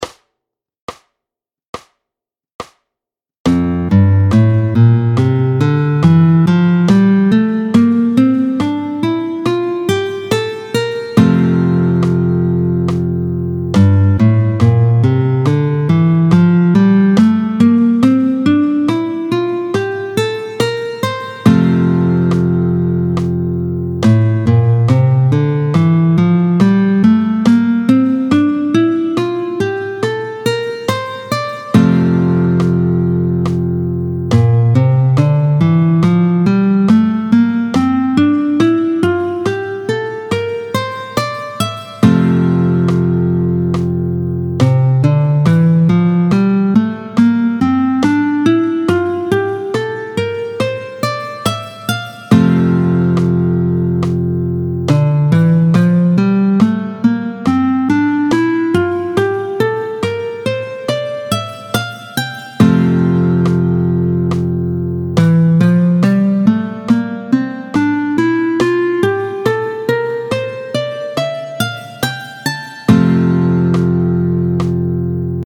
Les 7 doigtés en Fa majeur, tempo 70